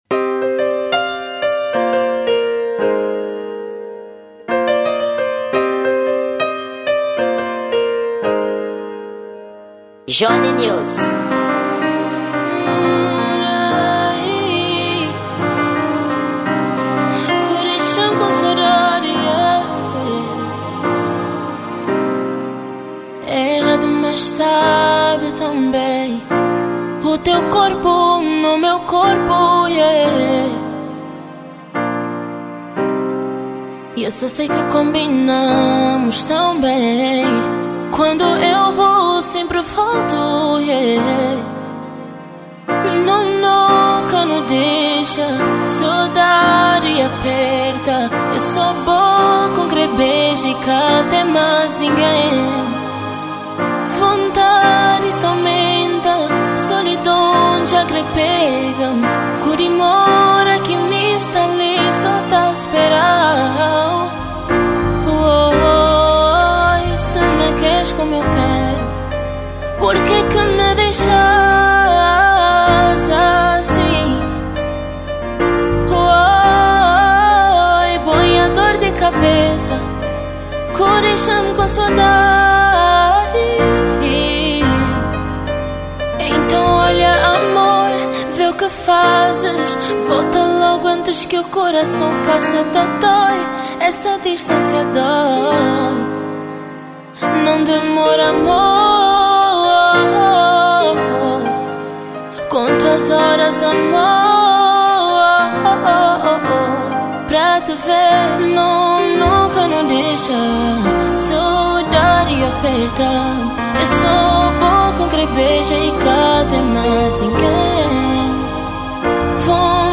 Gênero: Acústico